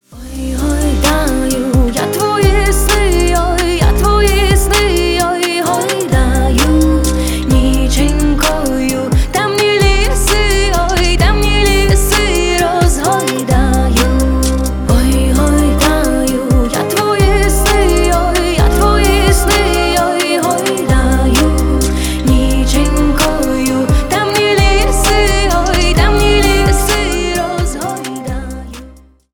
спокойные # грустные